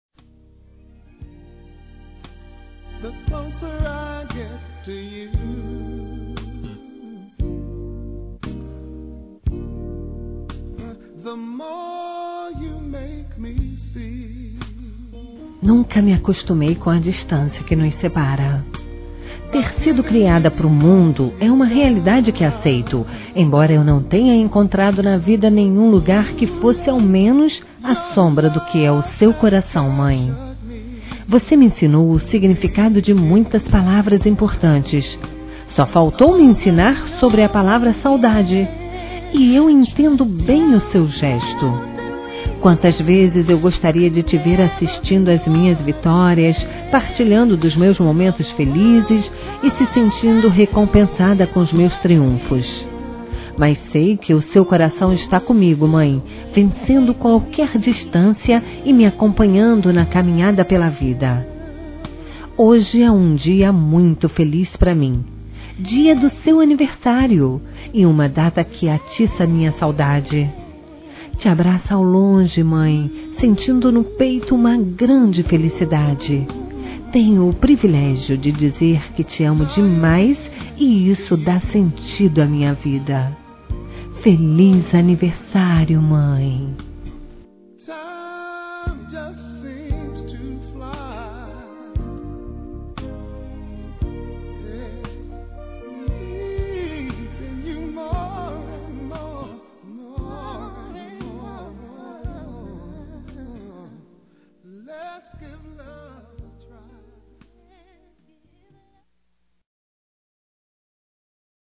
Telemensagem Aniversário de Mãe – Voz Feminina – Cód: 1419 – Distante